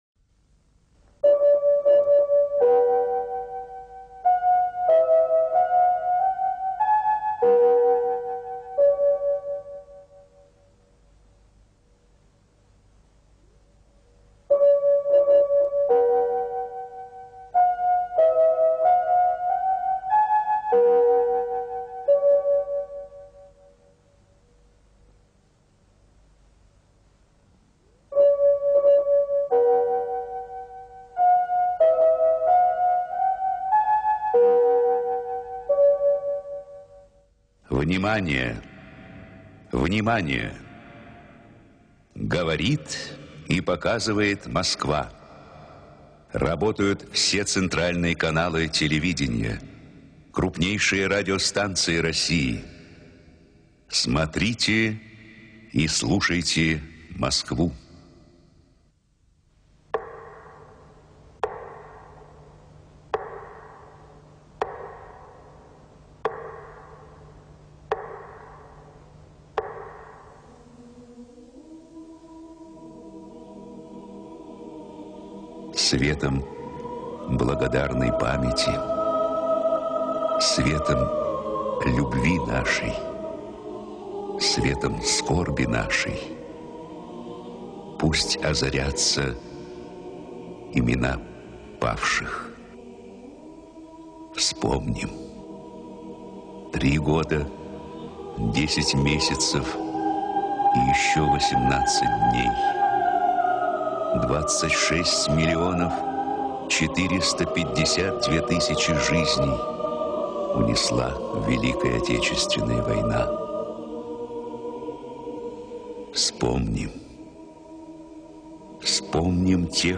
Текст читают Вера Енютина и Юрий Левитан .
Первая мелодия "Грёзы" Шумана
Исполняет: Вера Енютина и Юрий Левитан Исполнение 1968г.